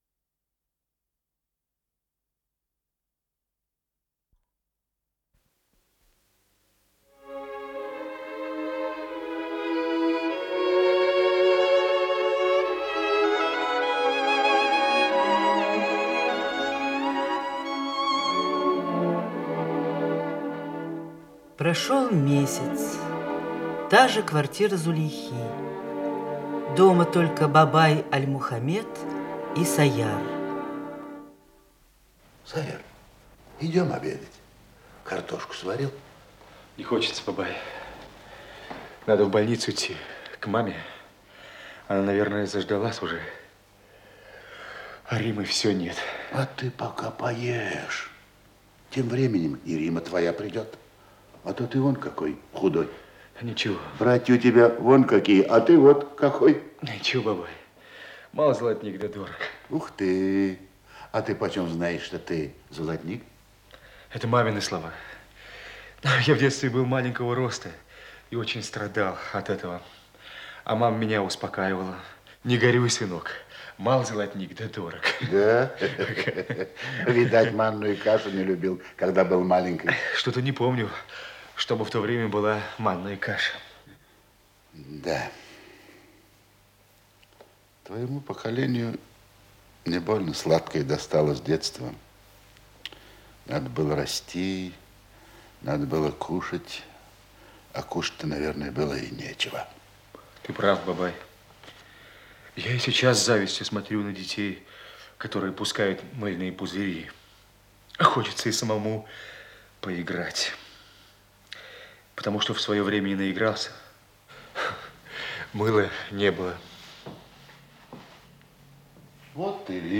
Оригинальная радиопьеса